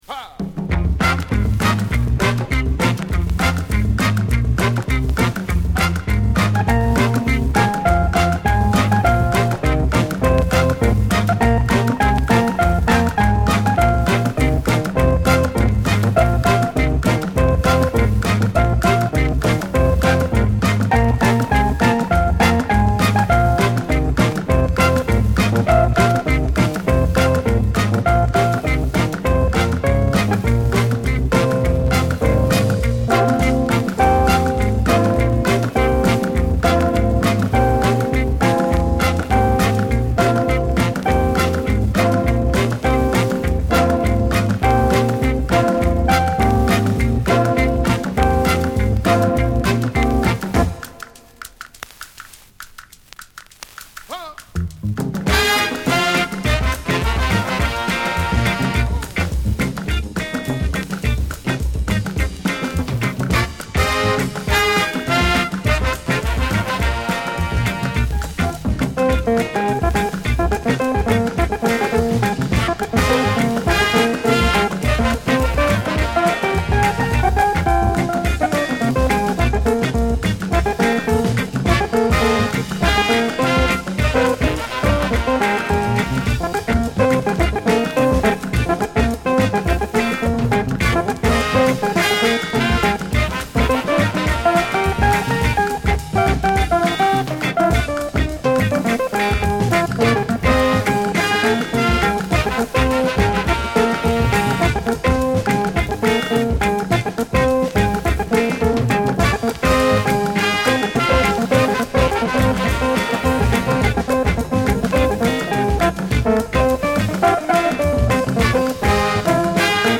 キレの良いリズム隊に高揚感あるホーン隊